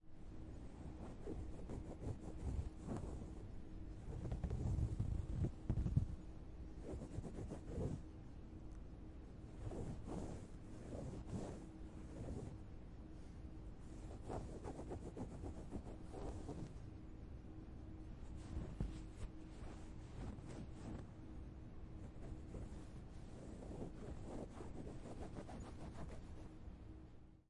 用指甲挠头发
描述：用指甲挠自己的头发，可用于各种皮肤瘙痒或动物爪子挠东西。
标签： 擦刮 挠痒
声道单声道